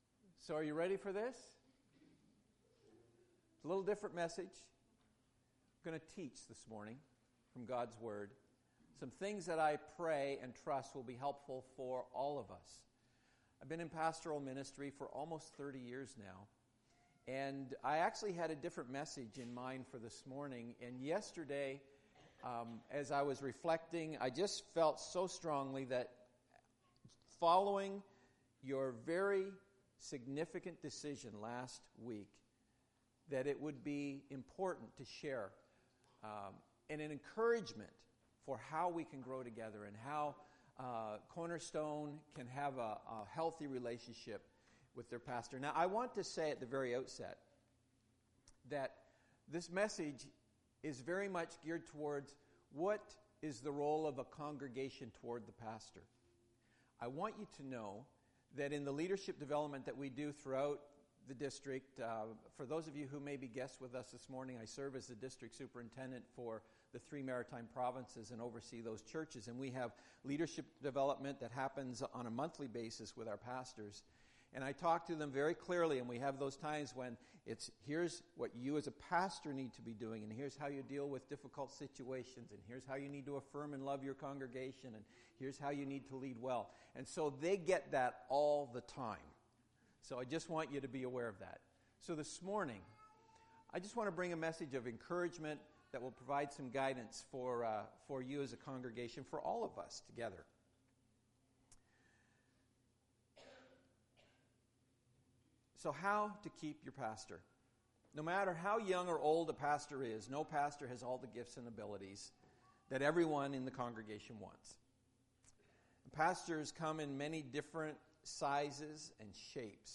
A Practical teaching